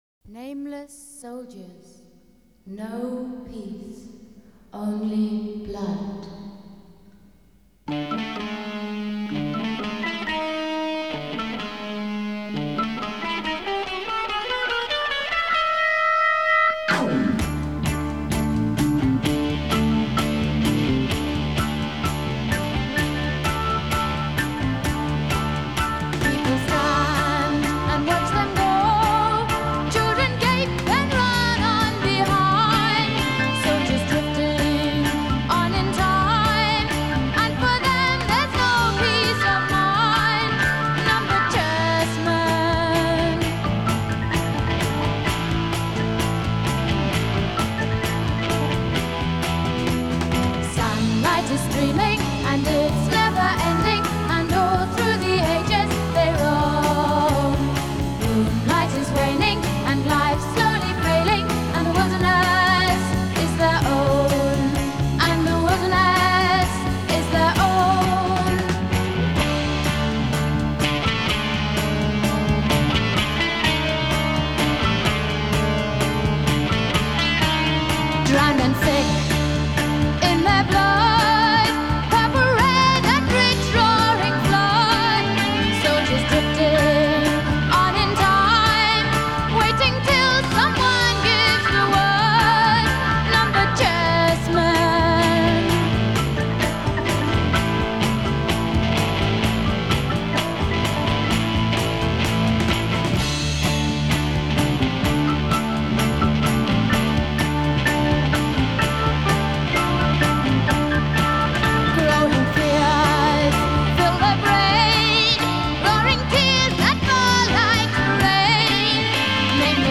early seventies prog rock band